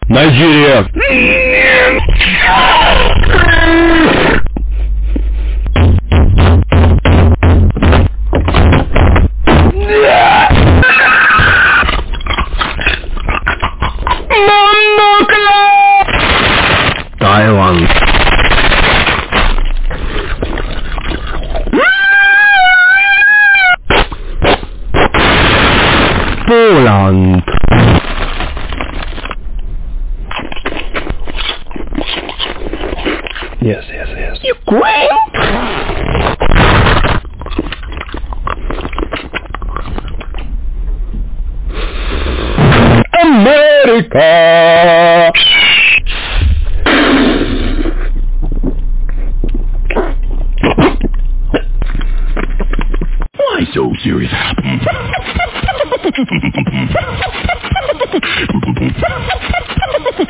Sushi Monster Asmr Sound Button: Unblocked Meme Soundboard